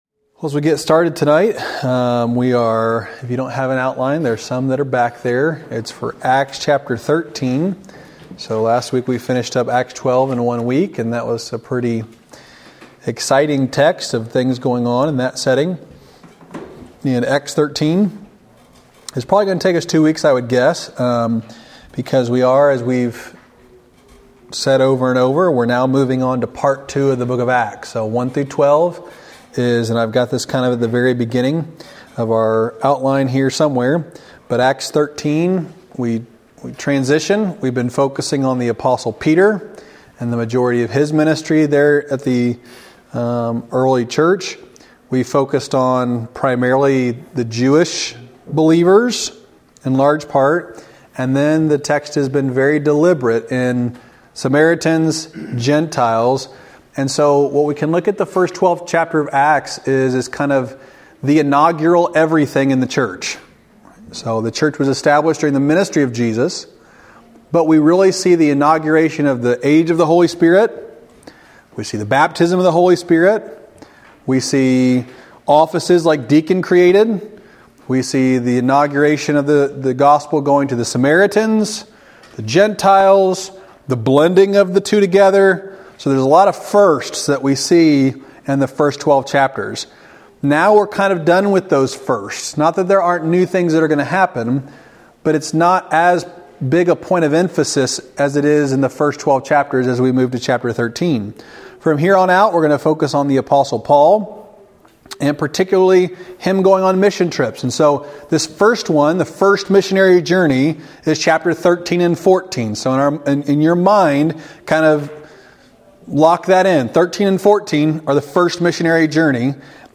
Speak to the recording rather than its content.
Wednesday night lesson from February 14, 2024 at Old Union Missionary Baptist Church in Bowling Green, Kentucky.